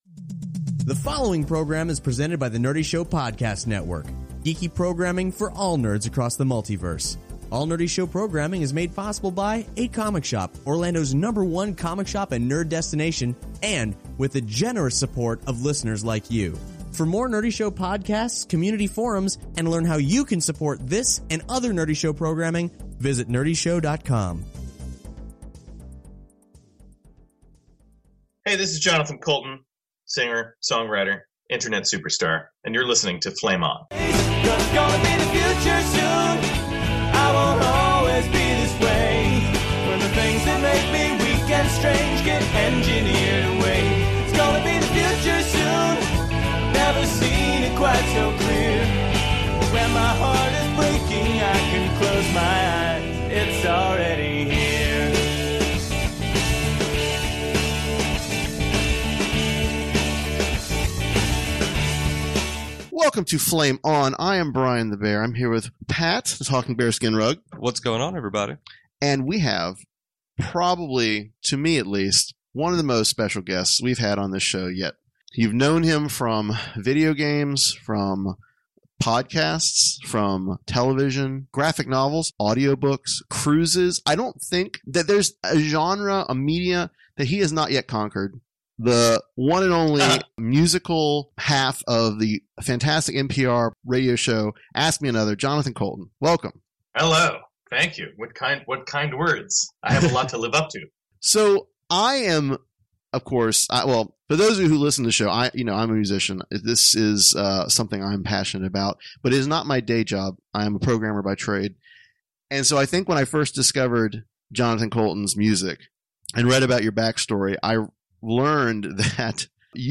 Episode 78 :: Jonathan Coulton Interview